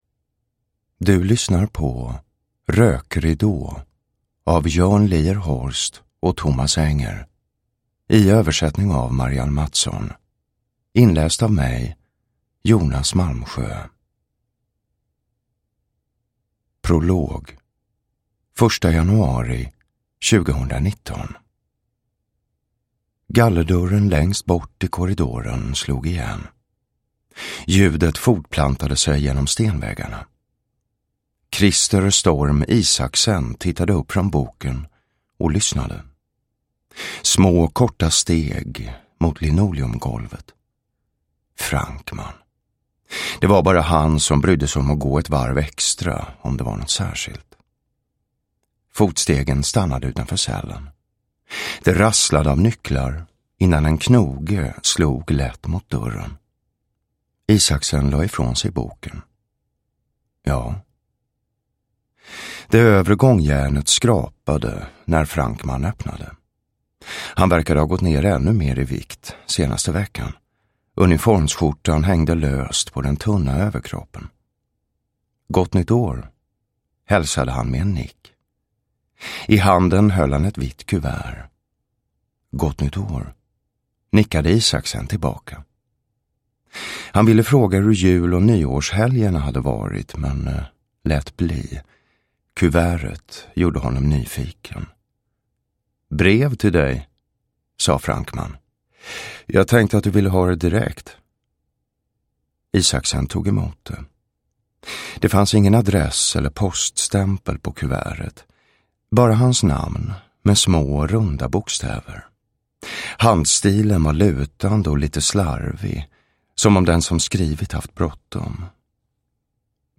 Rökridå – Ljudbok – Laddas ner
Uppläsare: Jonas Malmsjö